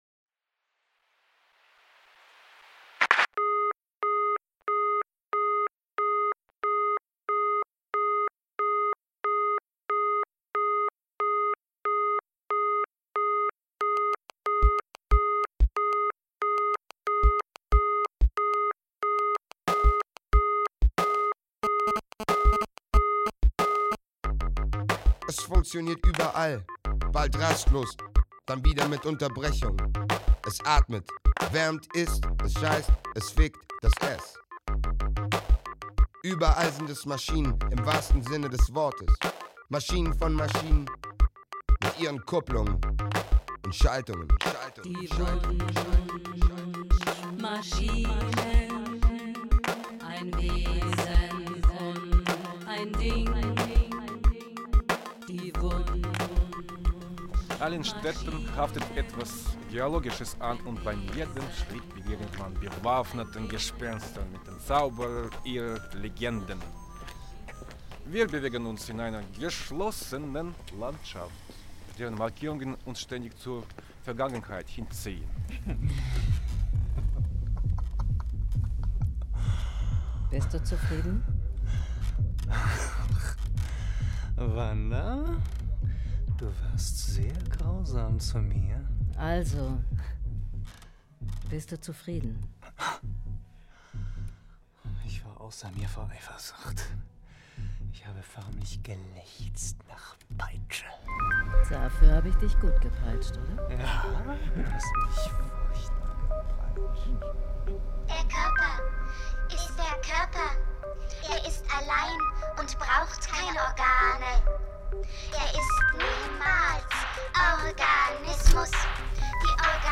surround radio play
The play is performed in acousmatic form (a loudspeaker performance) using so-called Ambisonics technology,
a special surround sound system that can create three-dimensional spatial sounds.